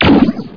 描述：ZTX软介质激光声
Tag: 激光